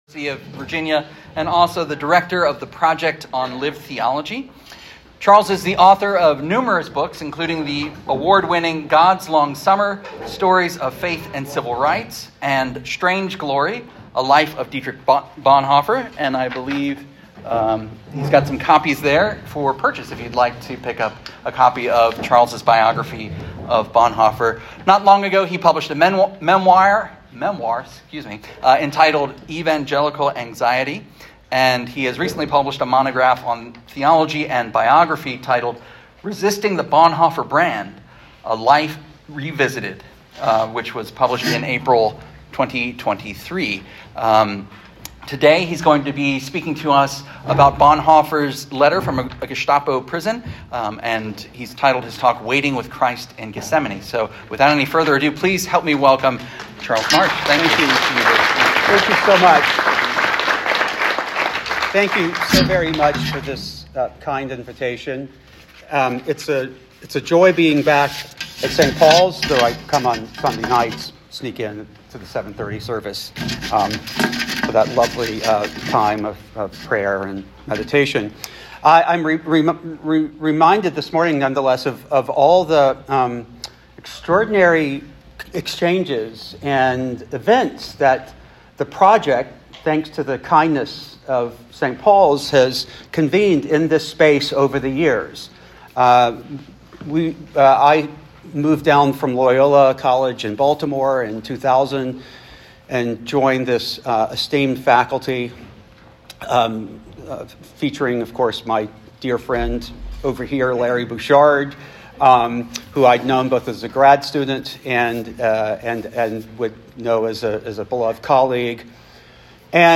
In this public talk from Charlottesville’s St. Paul’s Memorial Church on March 17, 2024
Audio Information Date Recorded: March 17, 2024 Location Recorded: Charlottesville, VA Audio File: Download File » This audio is published by the Project on Lived Theology (PLT).
St.-Pauls-Memorial-Church.mp3